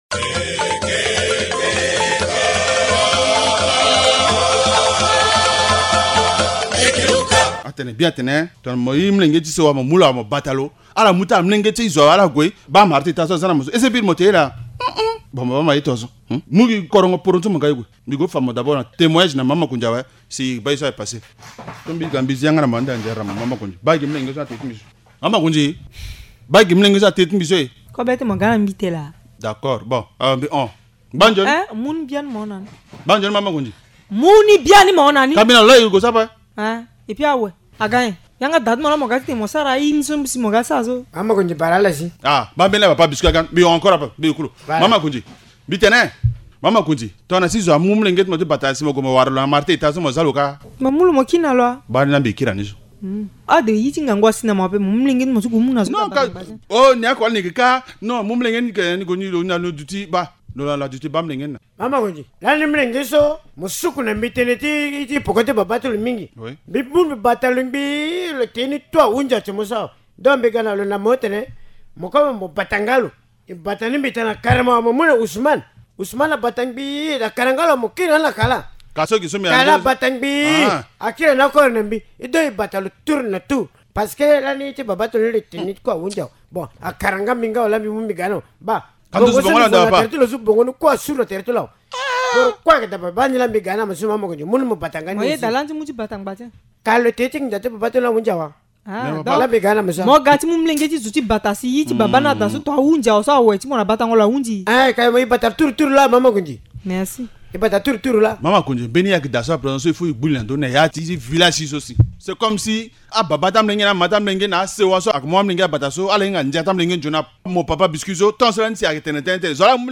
Linga théâtre : le droit des enfants bafoués par les parents du village